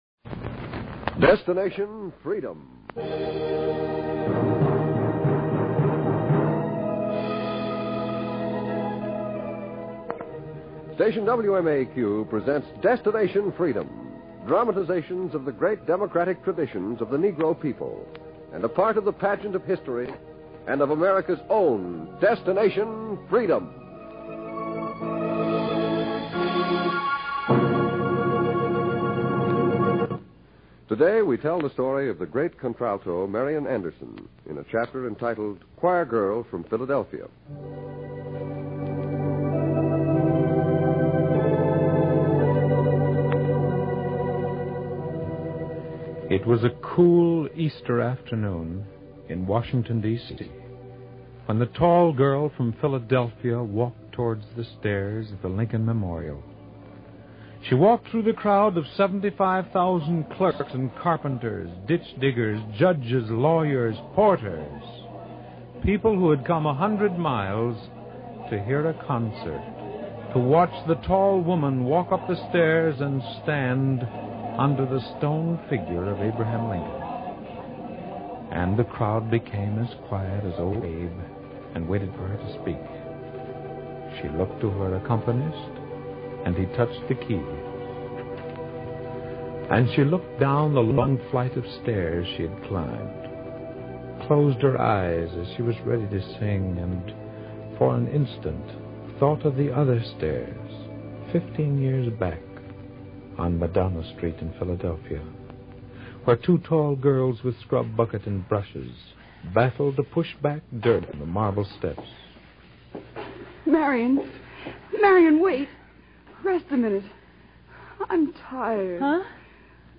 "Destination Freedom" was a pioneering radio series written by Richard Durham that aired from 1948 to 1950. The series aimed to highlight the achievements and struggles of African Americans, often focusing on historical figures and events that were underrepresented in mainstream media.